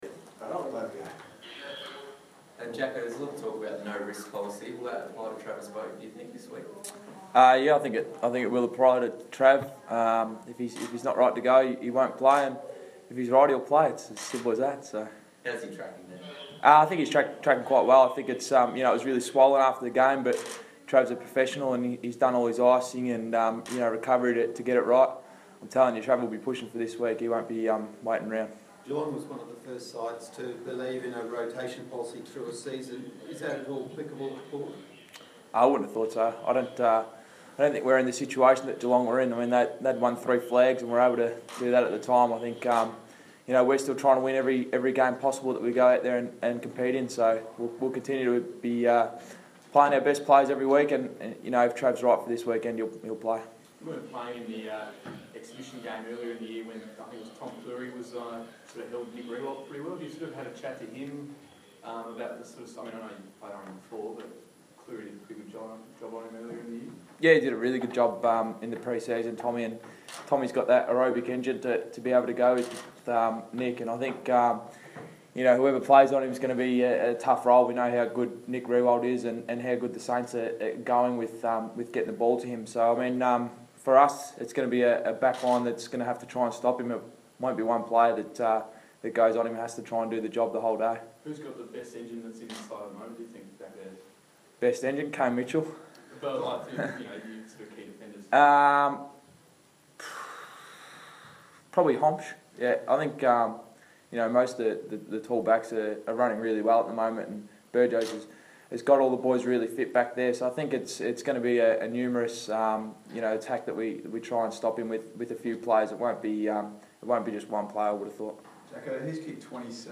Jackson Trengove press conference - Wednesday 4 June, 2014
Jackson Trengove speaks to the media ahead of the Power's clash with the Saints at Adelaide Oval.